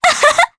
Artemia-Vox_Happy2_jp.wav